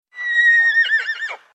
ACTIVITAT 8. QUIN ANIMAL FA AQUEST SOROLL?
cavall.mp3